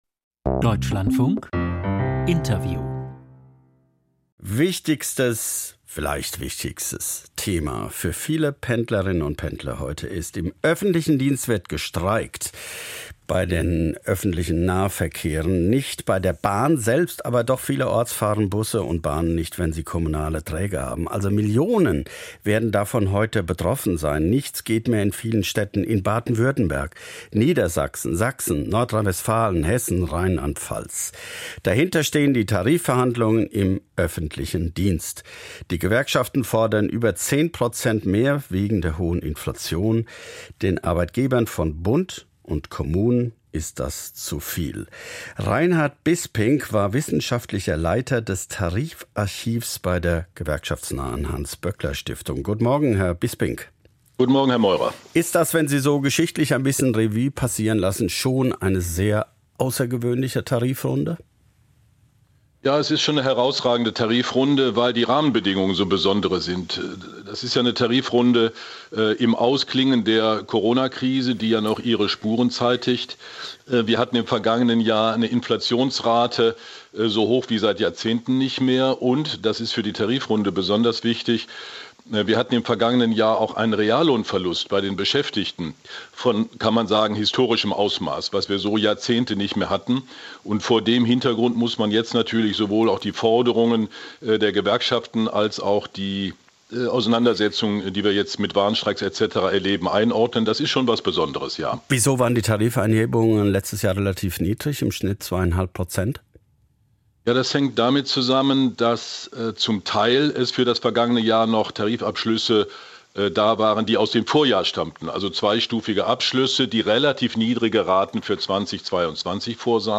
Interview: Die Warnstreiks im öffentlichen Dienst